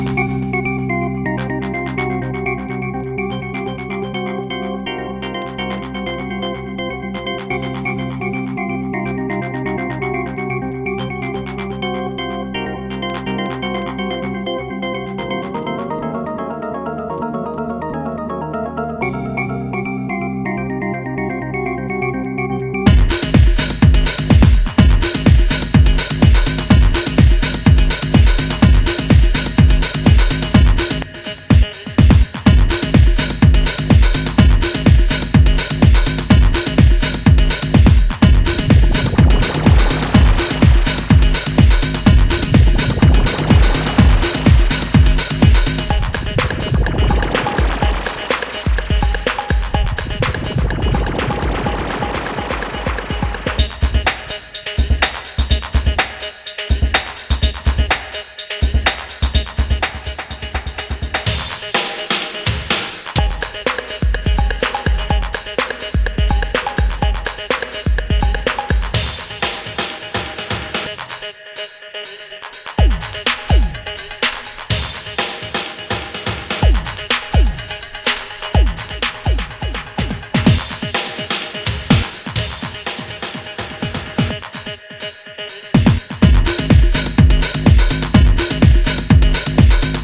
パワフルなハウスビートが不穏なブレイクを挟みながら80sエレクトロへと転調し舞い戻ってくる